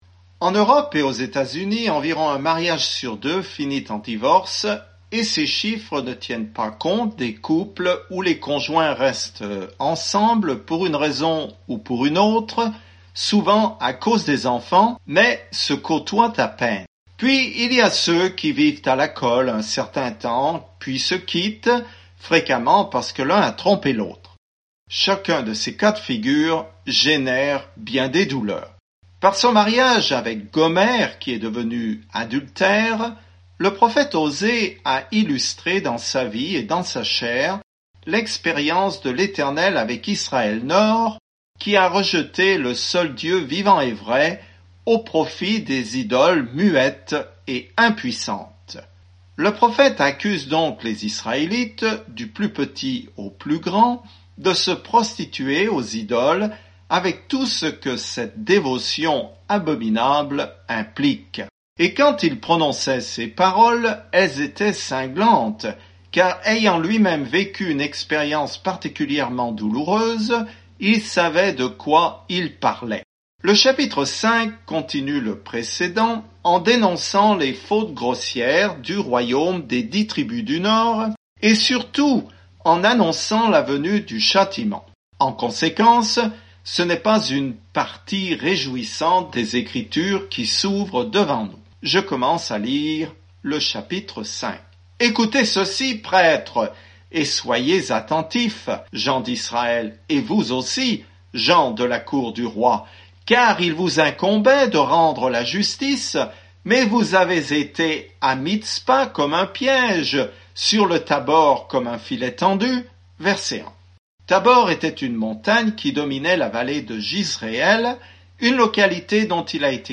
Écritures Osée 5:1-10 Jour 6 Commencer ce plan Jour 8 À propos de ce plan Dieu a utilisé le mariage douloureux d'Osée pour illustrer ce qu'il ressent lorsque son peuple lui est infidèle, mais il s'engage à continuer de l'aimer. Parcourez quotidiennement Osée en écoutant l’étude audio et en lisant certains versets de la parole de Dieu.